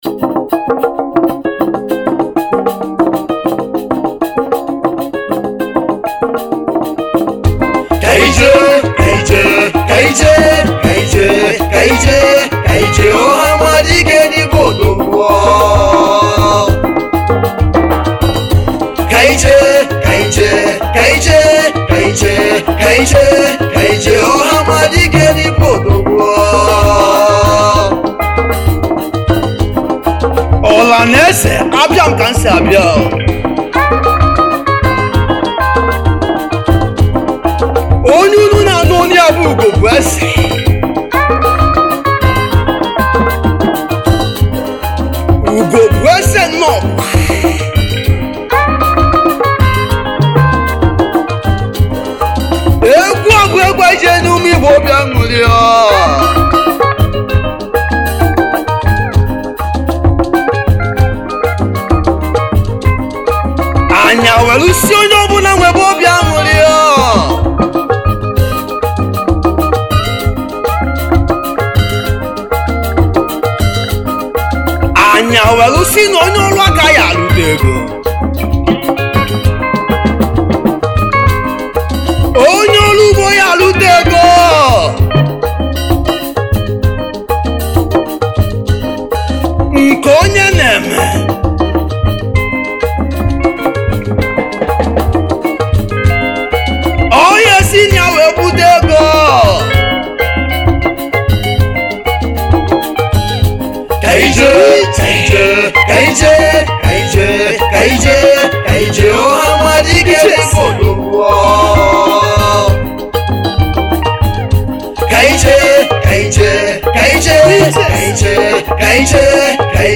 Highlife Traditional